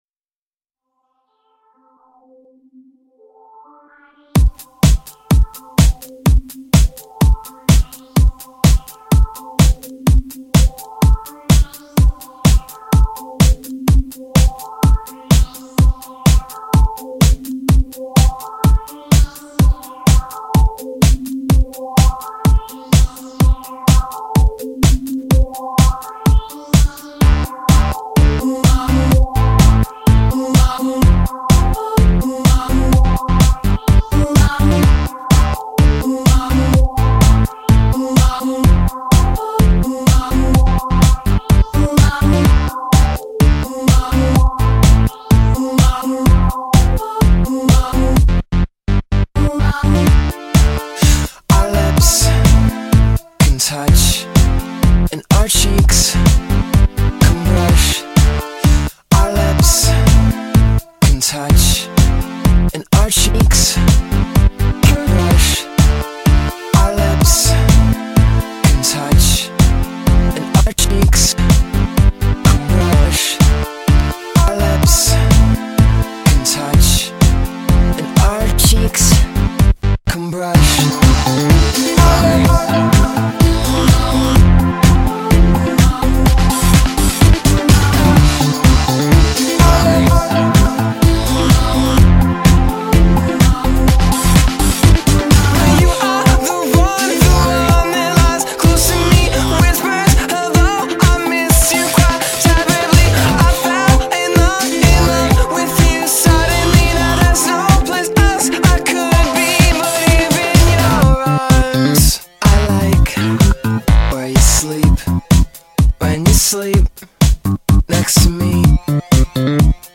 indie pop / power pop band
This is a solid remix